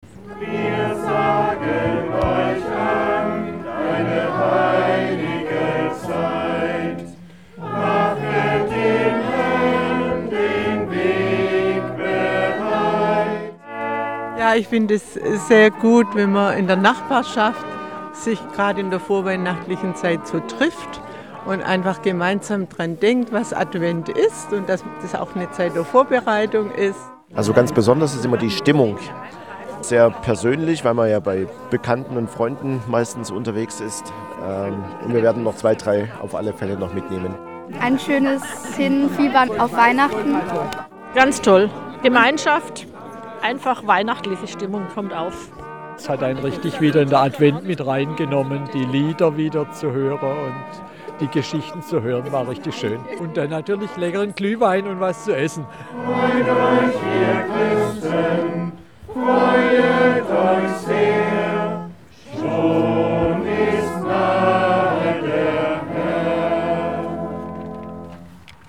Doch was macht den besonderen Reiz aus? Eine Radioreportage spürt dem nach.